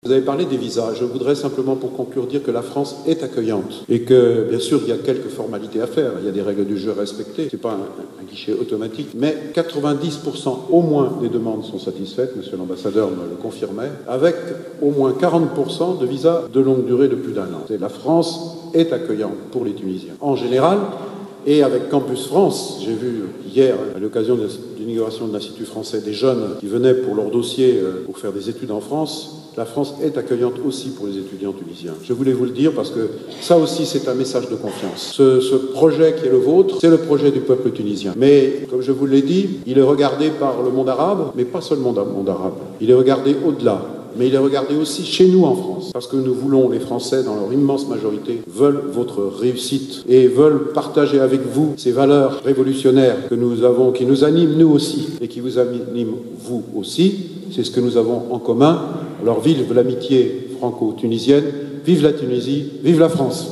أكد وزير الخارجية الفرنسي جون مارك إيرو في تصريح
على هامش ندوة صحفية